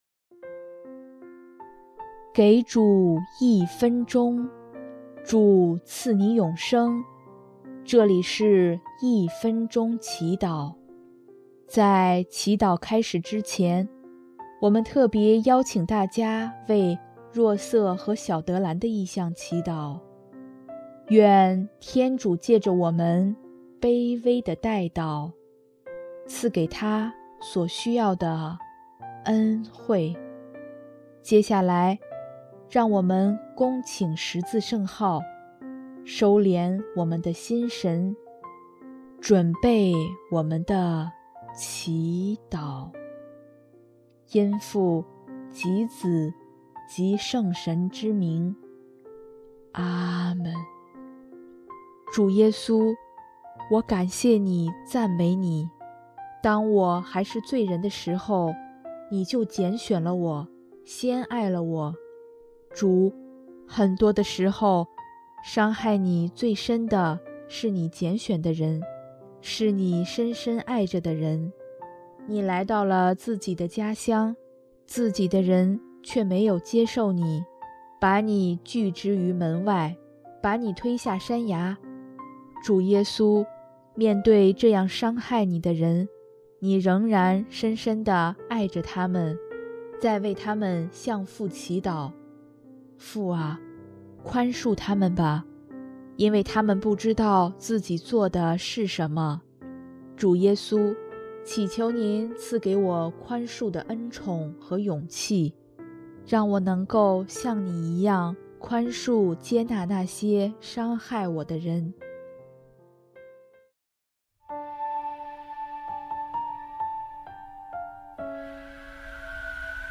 音乐 ：第四届华语圣歌大赛参赛歌曲《圣神之歌》（若瑟和小德兰：为全家不受骚扰祈祷）